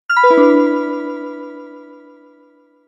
メール音やSMSの通知音にぴったりな長さの音です。
また、この音は古めかしい響きがあるため、レトロな雰囲気を出すための効果音としても用いられることがあります。